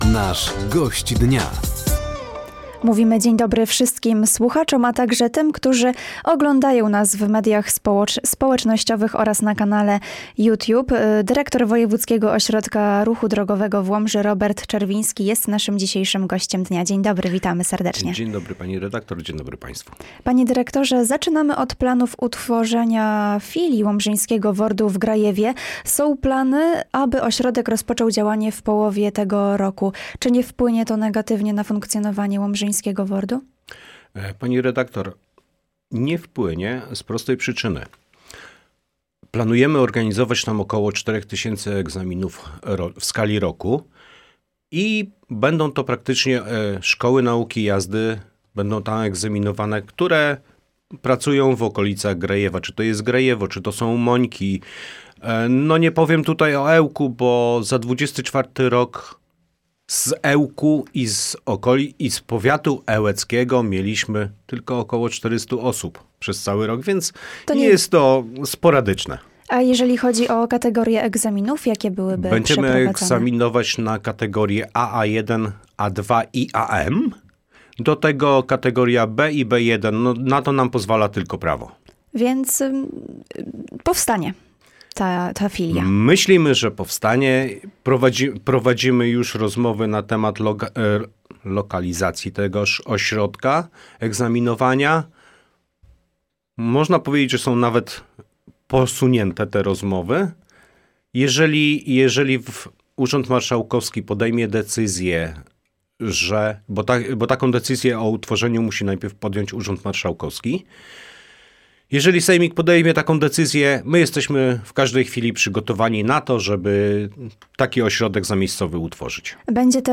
Plany utworzenia fili łomżyńskiego WORD-u w Grajewie, podwyżka opłat za egzaminy na prawo jazdy, a także bezpieczeństwo w ruchu drogowym – to główne tematy rozmowy z dzisiejszym Gościem Dnia.